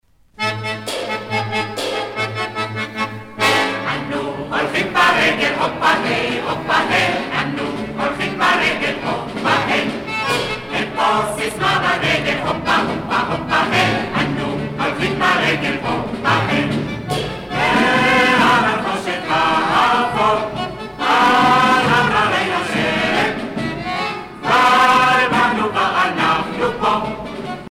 Chants patriotiques et de retour
Pièce musicale éditée